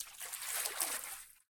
fishline1.ogg